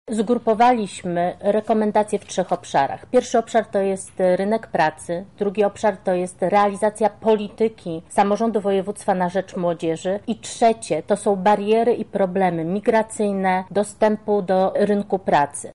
Księga dotyczy przede wszystkim edukacji wyższej, rynku pracy oraz rozwoju Lublina. Została ona podzielona na trzy różne obszary, jakie dokładnie mówi Bożena Lisowska, radna Sejmiku Województwa Lubelskiego